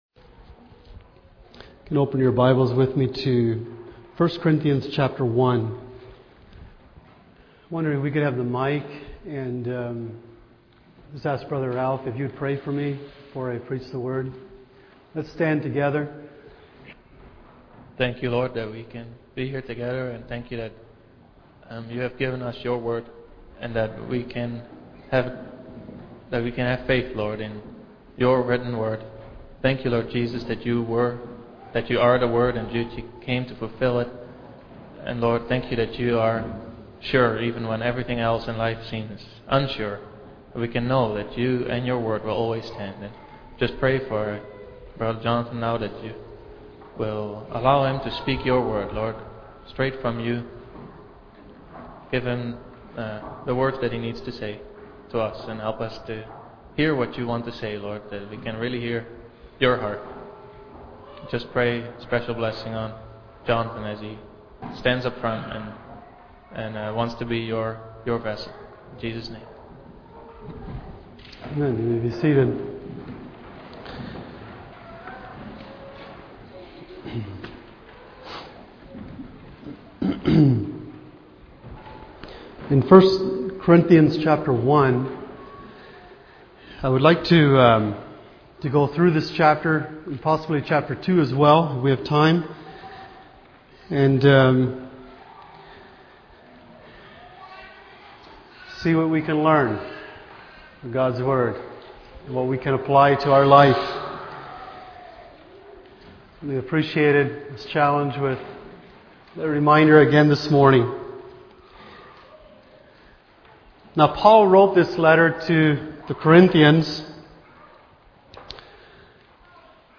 Passage: 1 Corinthians 1:1-31 Service Type: Sunday Morning